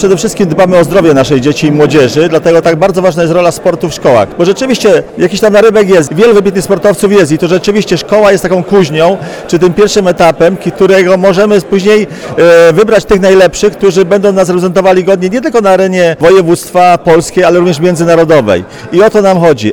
Na uroczystej I Powiatowej Gali Sportu w sali koncertowej Dworu Paderewskiego w Kąśnej Dolnej przyznano nagrody dla młodych sportowców, trenerów i zasłużonych działaczy sportowych.
Jak podkreśla starosta powiatu tarnowskiego Roman Łucarz, najistotniejsza jest kondycja fizyczna młodych ludzi, która rozwija się dzięki takim wydarzeniom.